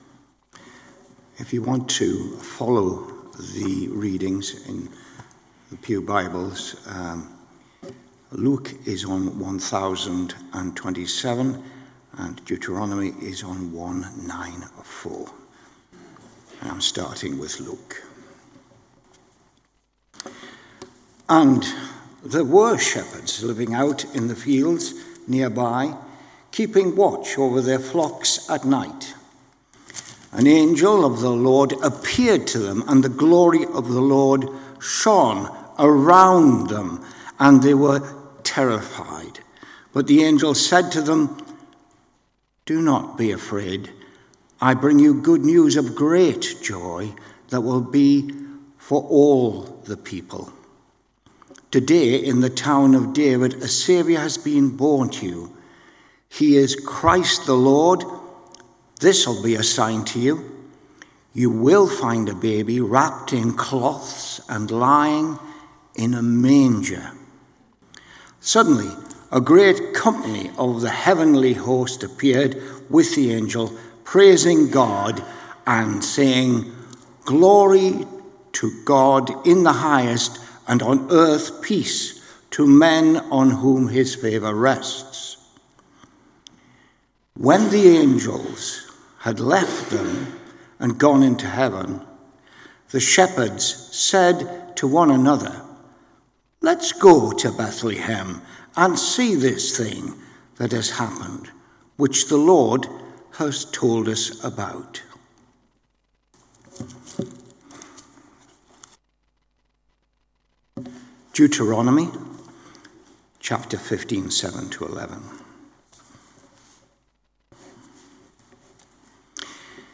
Luke , Deuteronomy Watch Listen Save Luke 2: 8 – 15 & Deuteronomy 15: 7 – 11 Tagged with Morning Service , Advent Audio (MP3) 27 MB Previous A New World Next Wise Men